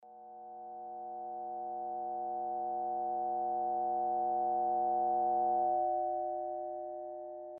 ambientsscape-4.mp3